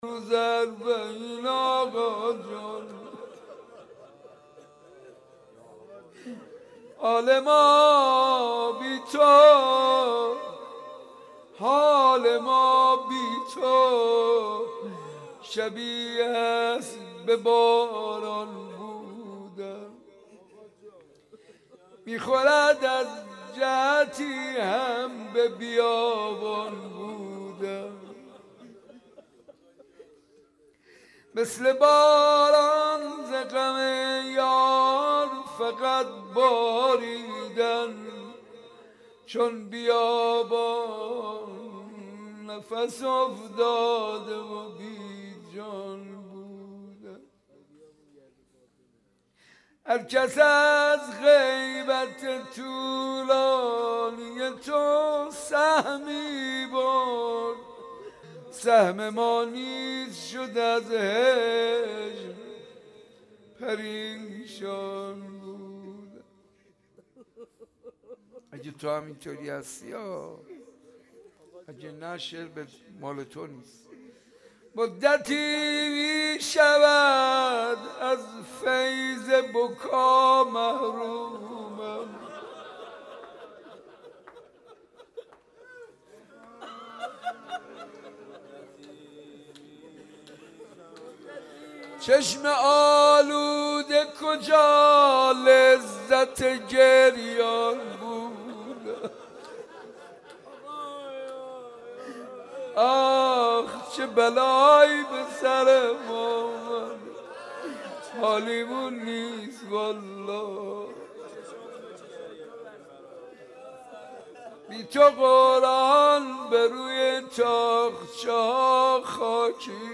با مداحی حاج منصور ارضی برگزار گردید.